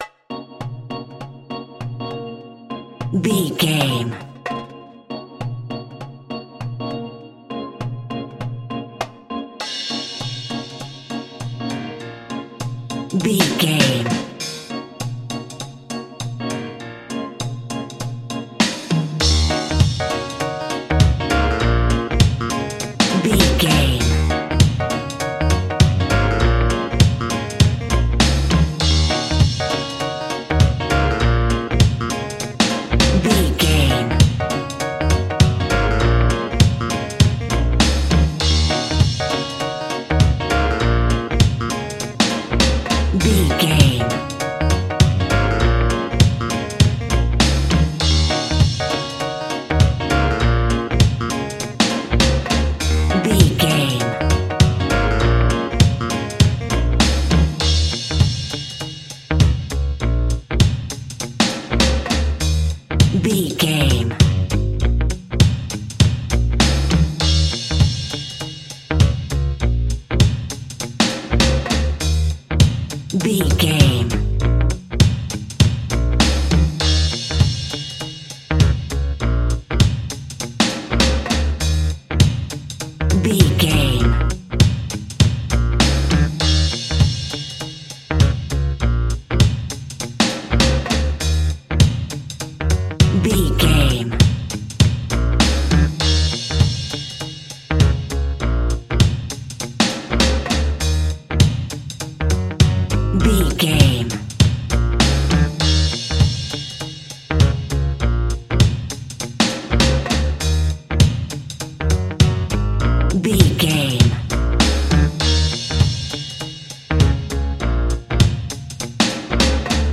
Aeolian/Minor
B♭
dub
laid back
chilled
off beat
drums
skank guitar
hammond organ
transistor guitar
percussion
horns